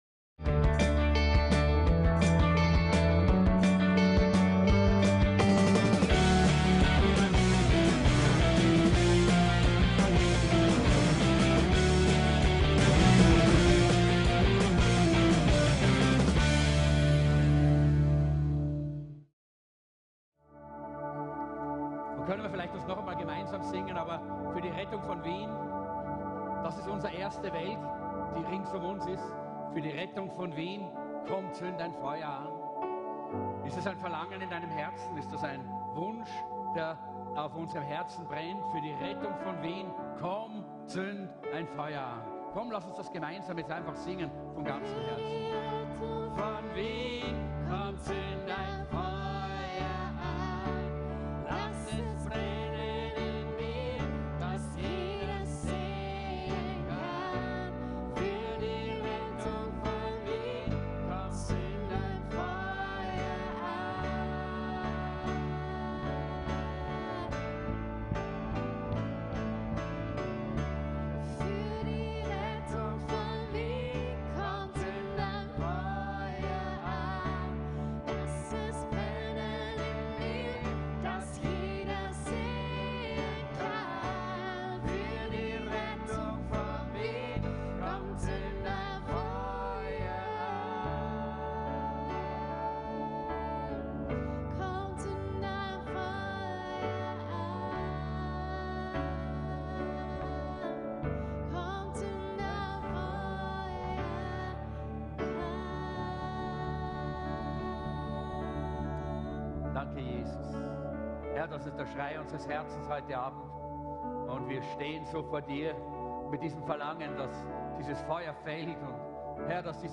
VISIONSPREDIGTSERIE 2019